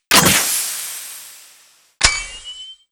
rifles.wav